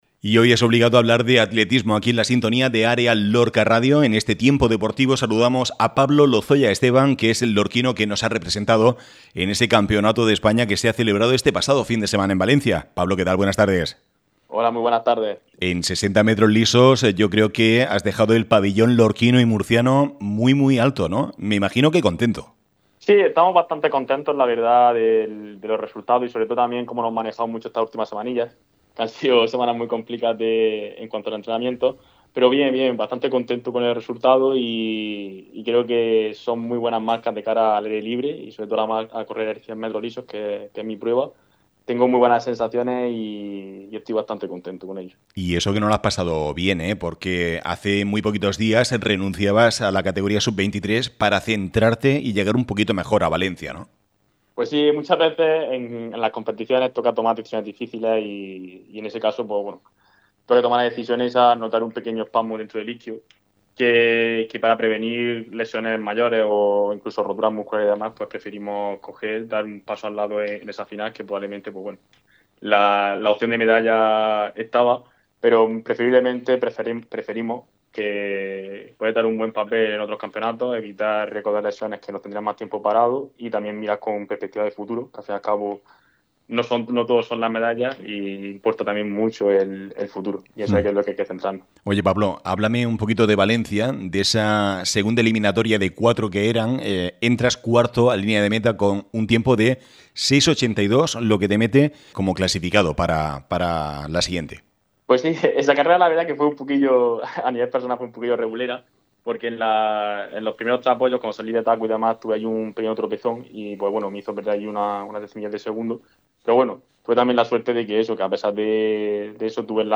en el programa deportivo de Área Lorca Radio